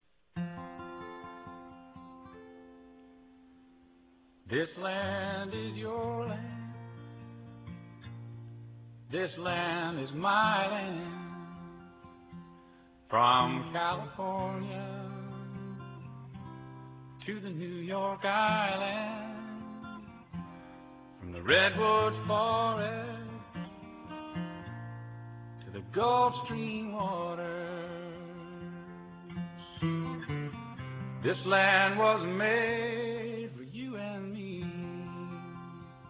Order original cast recording right from this site: